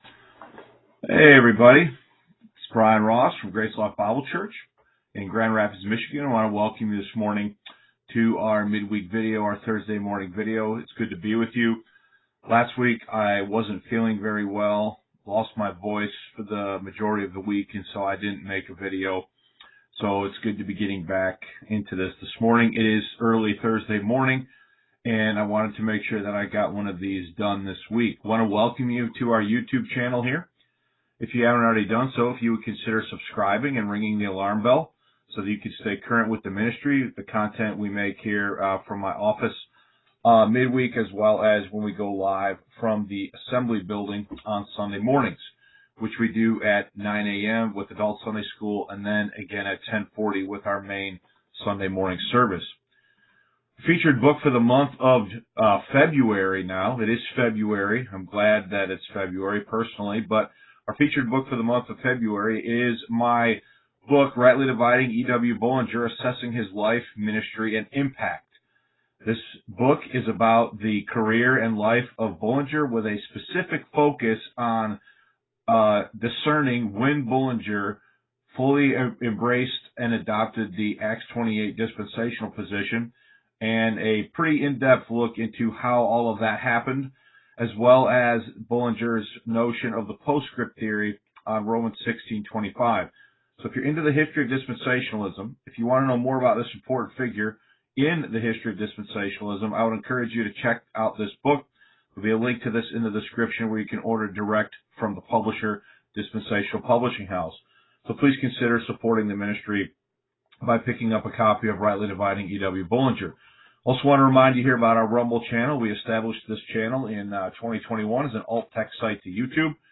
Facebook Live Videos (Vlogs) , Mid-Week Messages